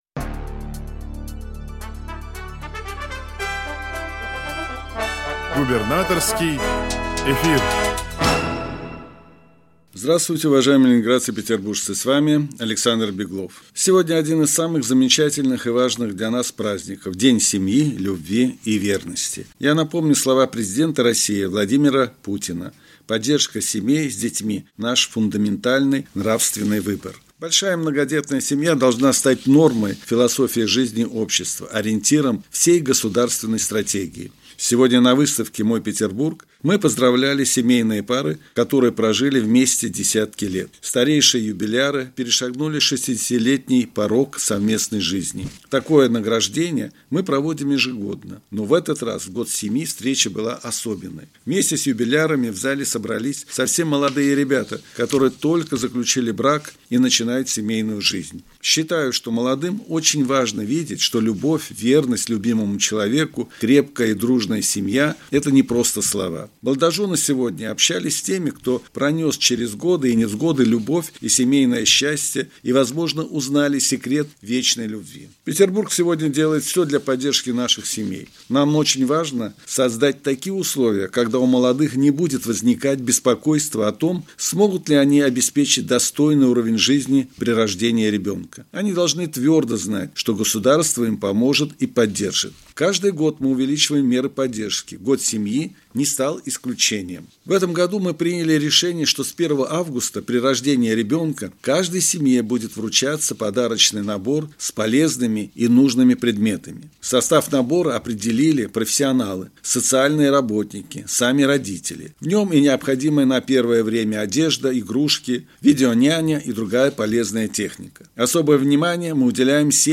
Радиообращение – 08 июля 2024 года